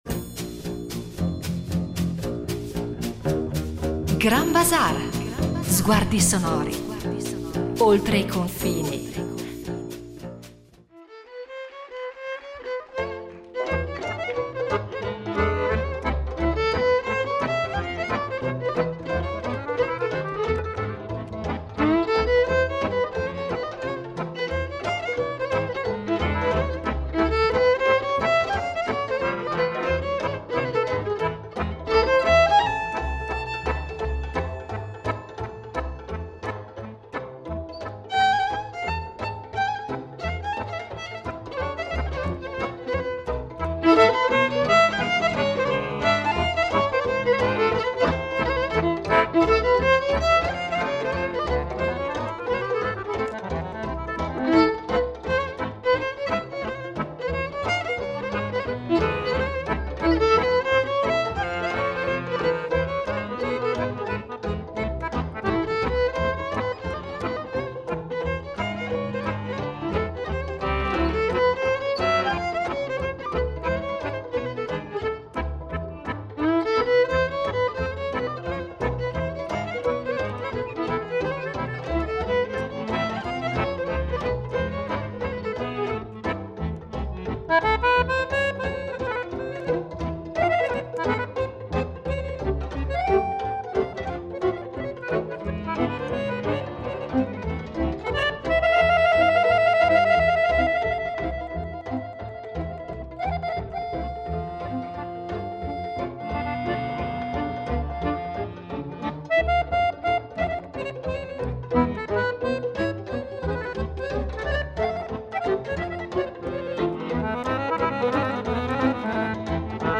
Muzică lăutărească
La musica lautaresca sarà al centro di quesat puntata, cioè musica suonata dai lautari, musicisti di etnia rom. Questa musica è complessa ed elaborata, con dense armonie e raffinati ornamenti.